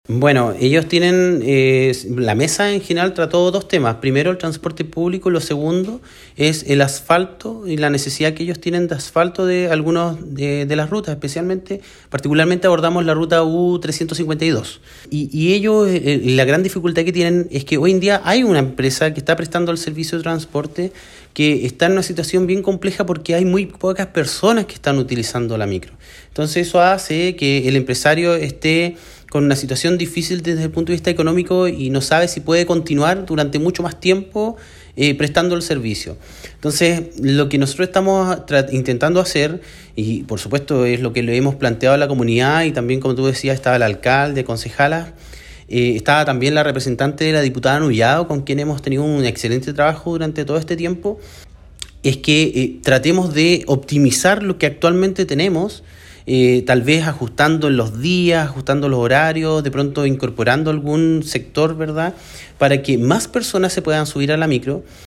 Una de las temáticas que se abordó durante esta instancia fue la necesidad de transporte público y el asfaltado de la Ruta U-352, puesto que a la fecha existe una empresa que presta el servicio pero la demanda ha bajado considerablemente, como lo explicó el Seremi de Transportes y Telecomunicaciones, Pablo Joost.